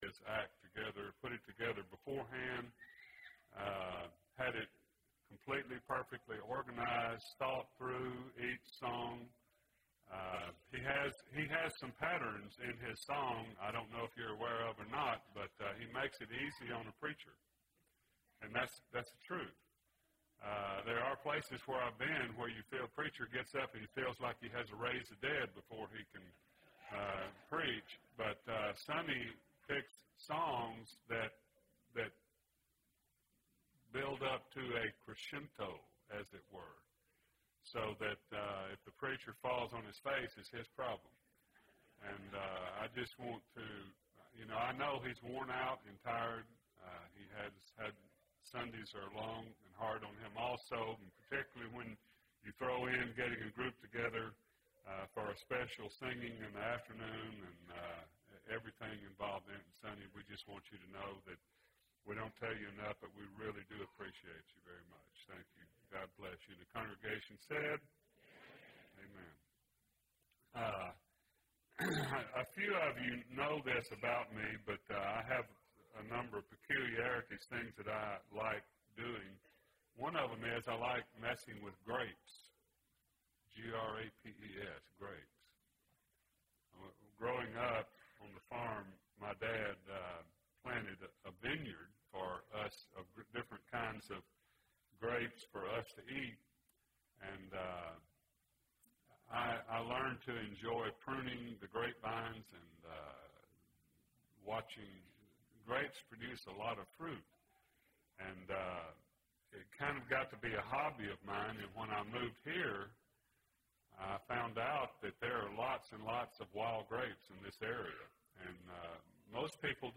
Sunday PM Sermon